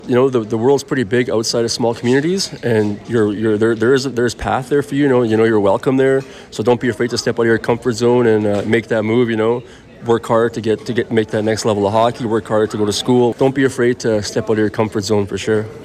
spoke with CFWE on his full circle moment returning to Lac La Biche to give back towards the youth from several First Nations communities for the youth conference.